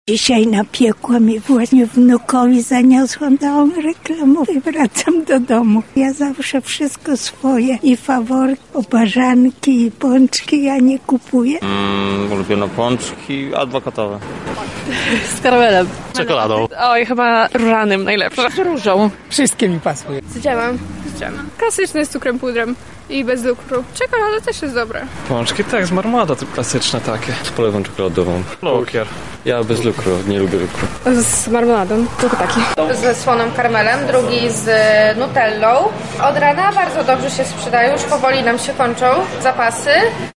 Zapytaliśmy mieszkańców Lublina, po jakie pączki sięgają najchętniej:
Sonda